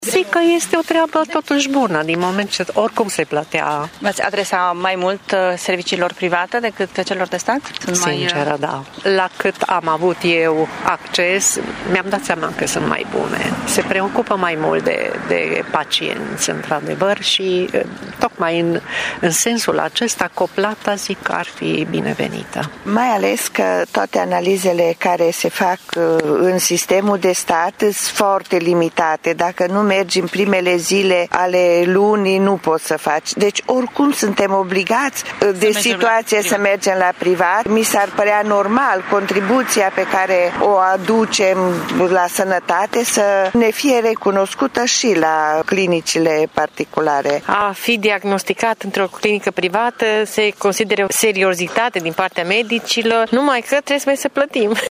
Unii dintre târgumureșeni cred că soluția coplății este una binevenită, mai ales că multe dintre investigațiile medicale se fac și acum la privat, dar pe bani: